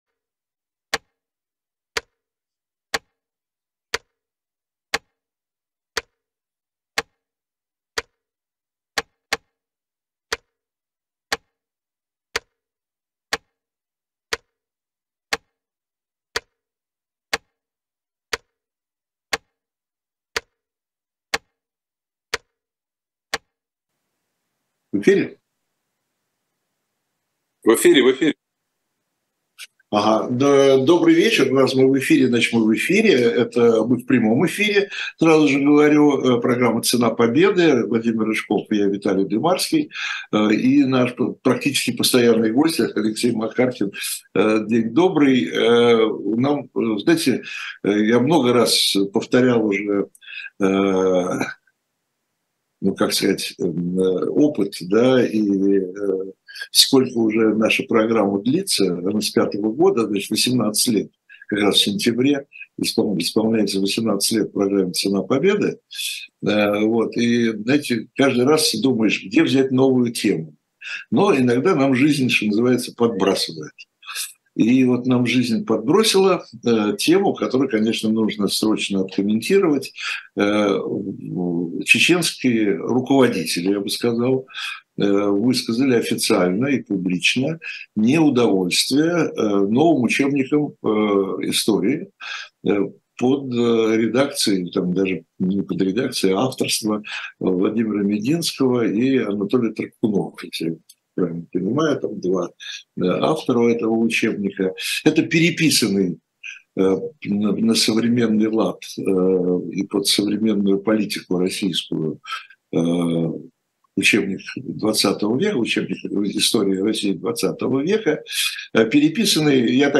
Гость - Алексей Макаркин. Ведущий - Виталий Дымарский